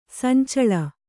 ♪ sancaḷa